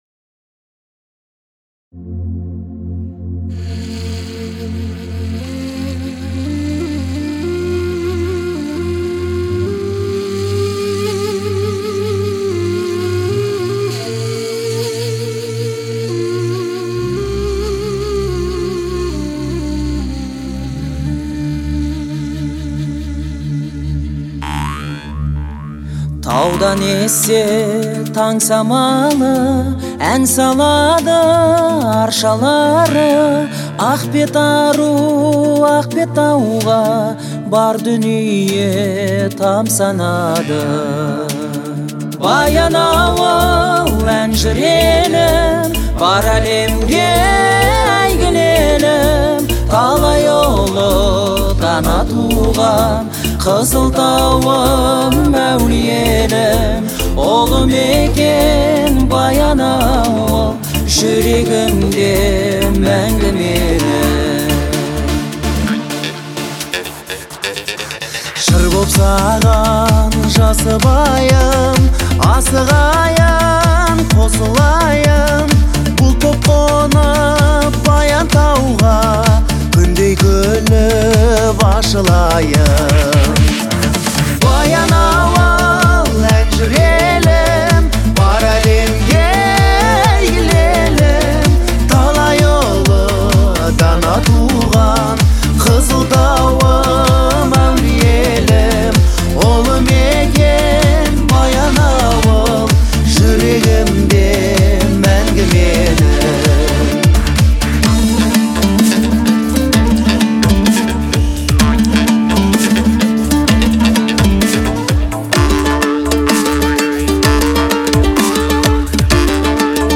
относится к жанру рок и обладает меланхоличным настроением.